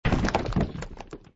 snd_rock_break.mp3